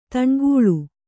♪ taṇgūḷu